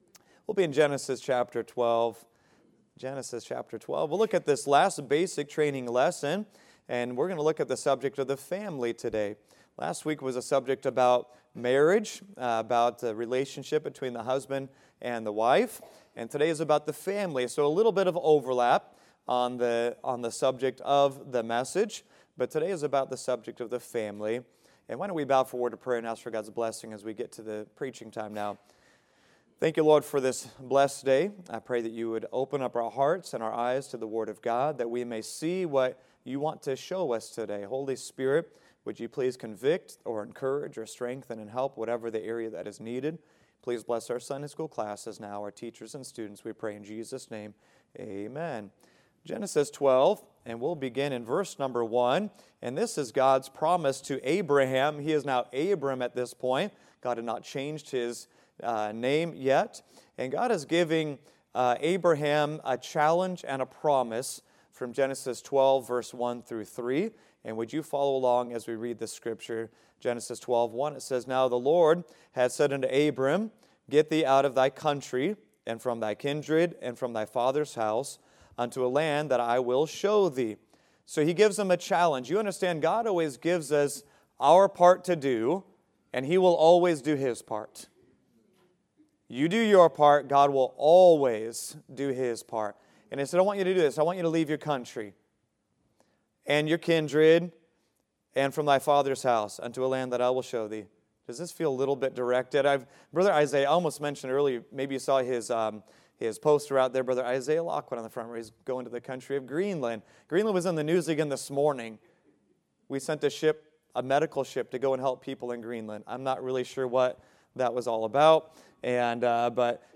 Family | Sunday School